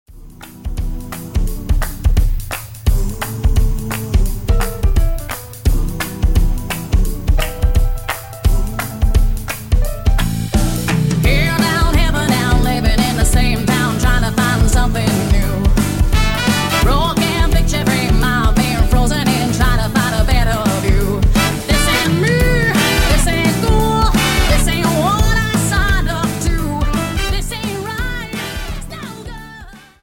Dance: Jive 43